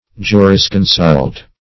Jurisconsult \Ju`ris*con"sult\, n. [L. jurisconsultus; jus,